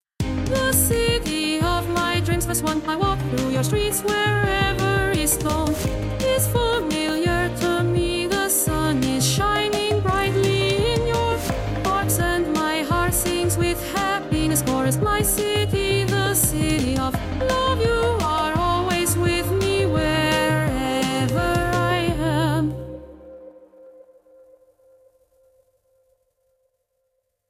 не естественный вокал
Stay-With-Me-with-Voicemod-Text-to-Song-2.mp3